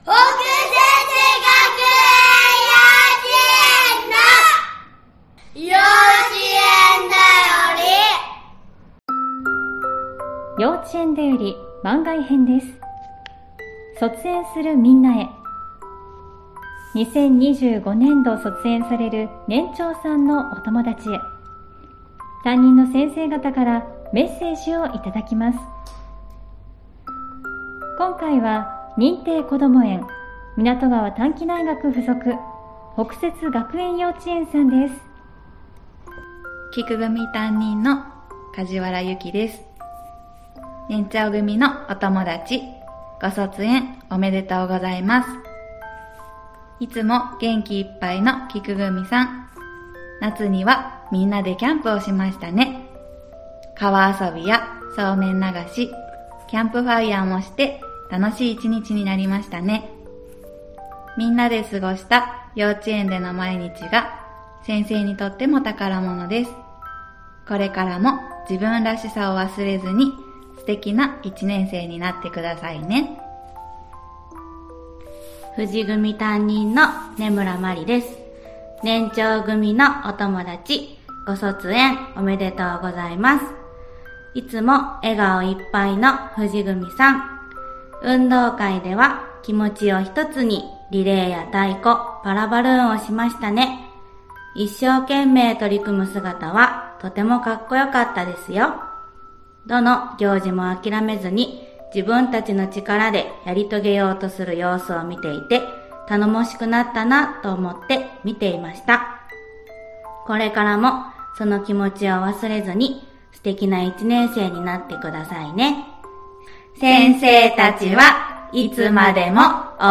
2025年度卒園する年長さんへ、先生方からのメッセージをお届けします！